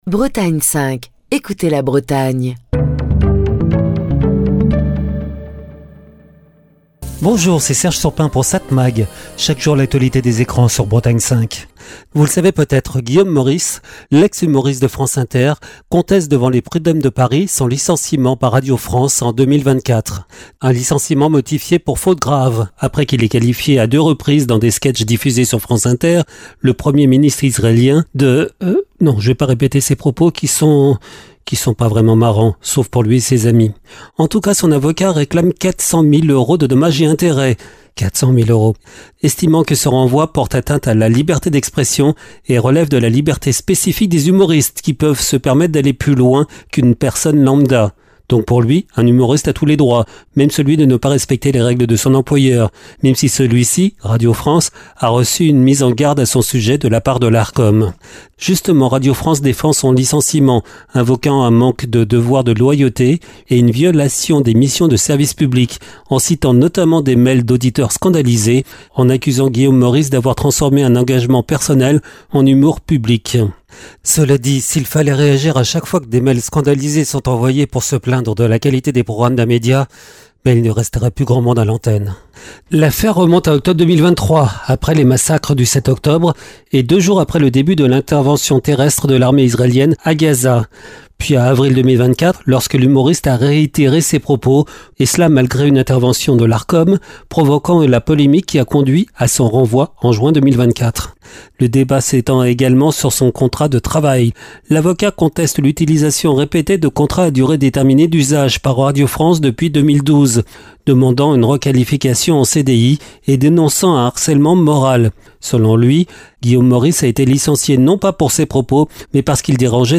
Chronique du 15 décembre 2025.